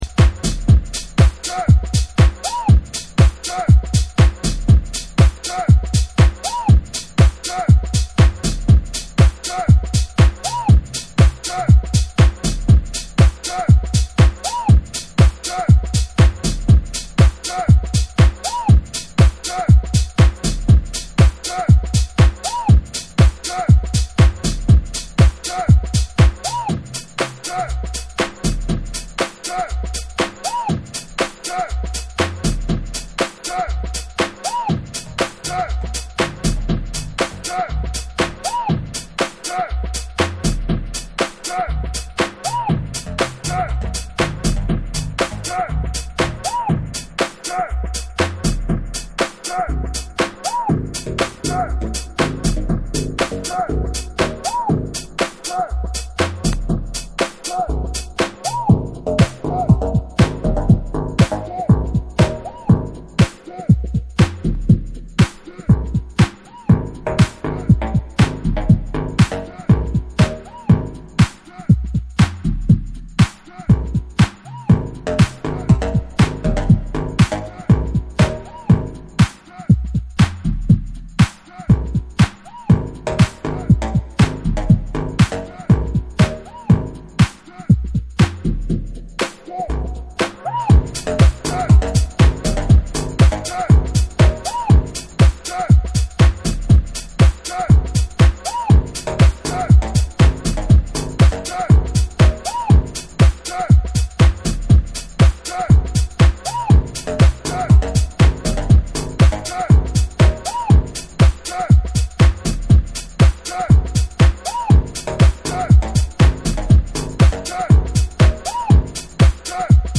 minimal and house cuts